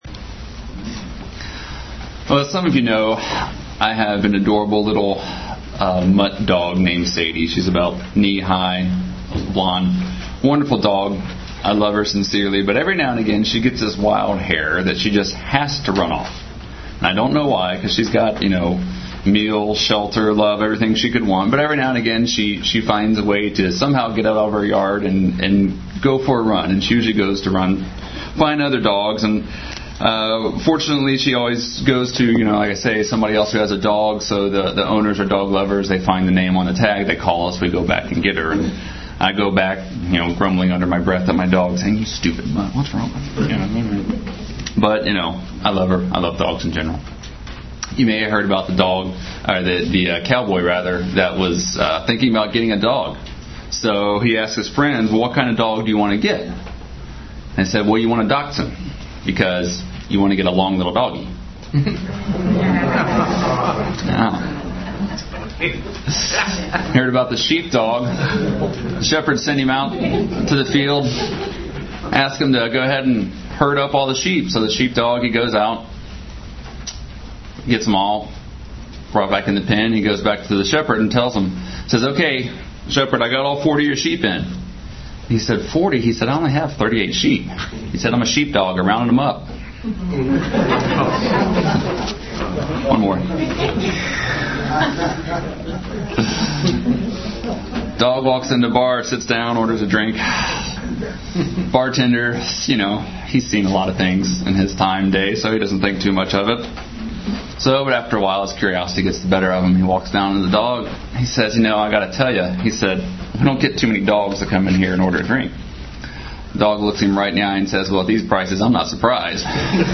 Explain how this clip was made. Given in Cincinnati North, OH